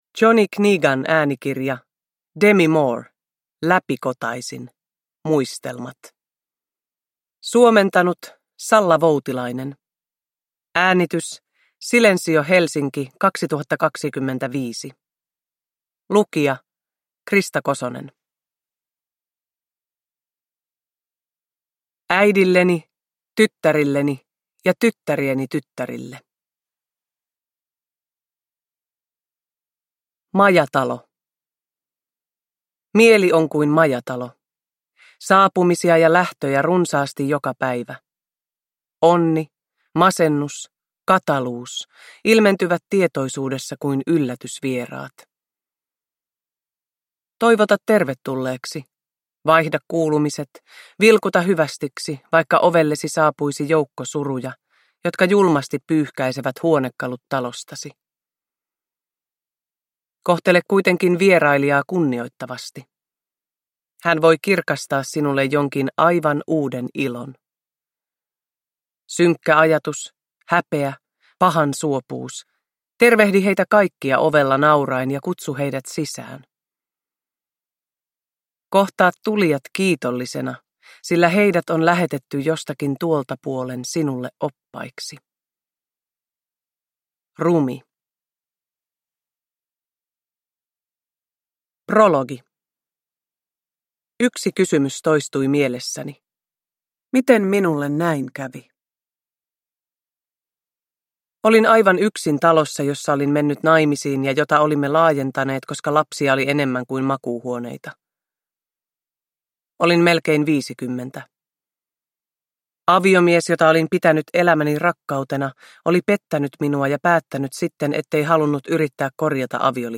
Läpikotaisin – Ljudbok
Uppläsare: Krista Kosonen